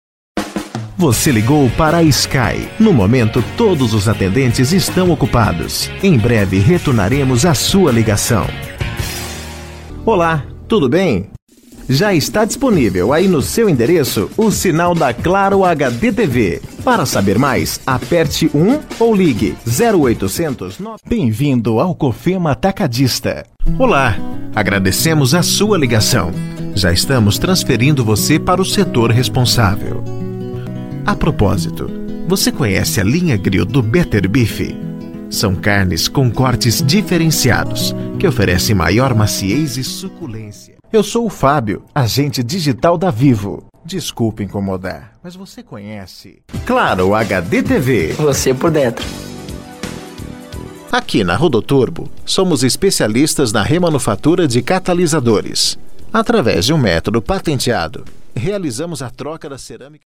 URA - ESPERA TELEFONICA